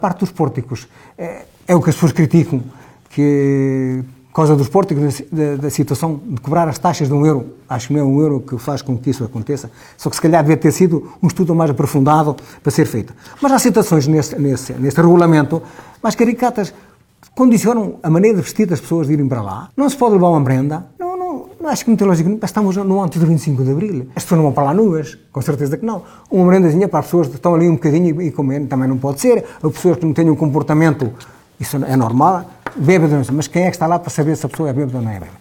Outra ideia deixada na grande entrevista que fez com a Rádio ONDA LIVRE é garantir o acesso, à Albufeira do Azibo, sem restrições ou de forma condicionada, por «regulamentos abusivos»: